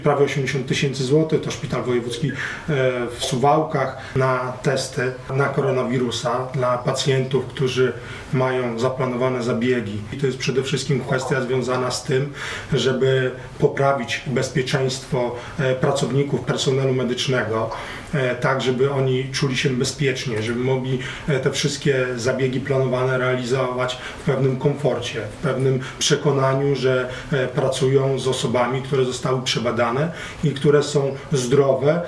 Jak powiedział Artur Kosicki, marszałek województwa, personel medyczny musi mieć pewność, że operowany pacjent nie jest zakażony.